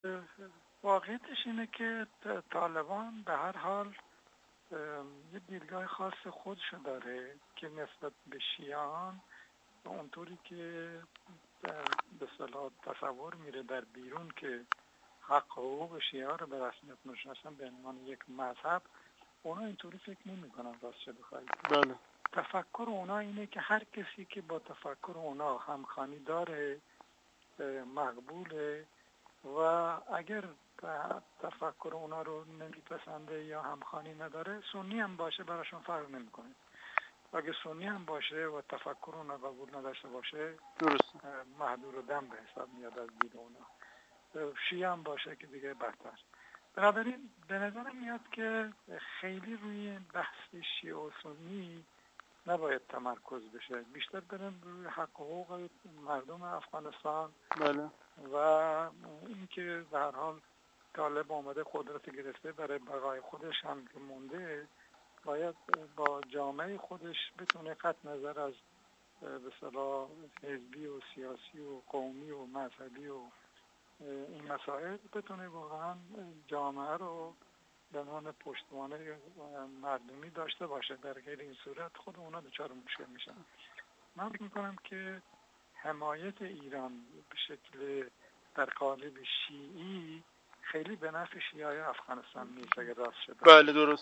کارشناس مسائل افغانستان
گفت‌وگو